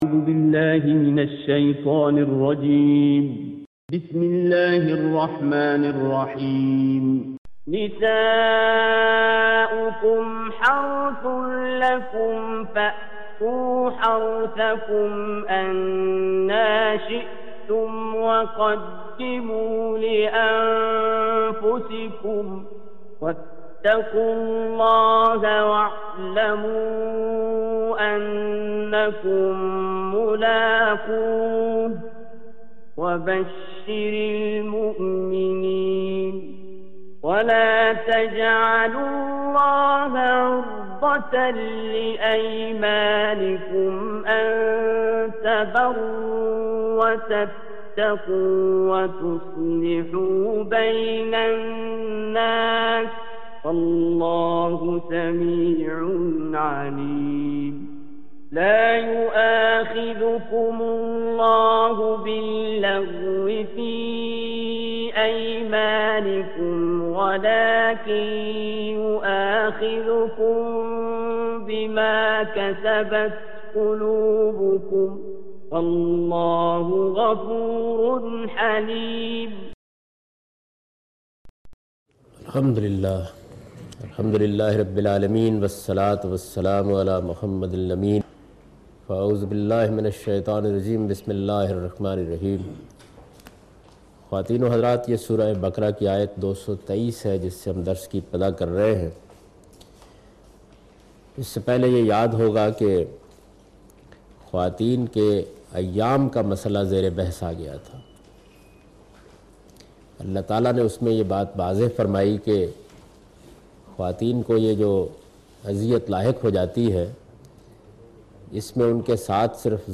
Surah Al-Baqarah - A Lecture of Tafseer ul Quran Al-Bayan by Javed Ahmed Ghamidi.